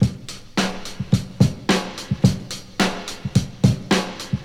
• 108 Bpm Drum Loop B Key.wav
Free drum loop sample - kick tuned to the B note. Loudest frequency: 1304Hz
108-bpm-drum-loop-b-key-NXP.wav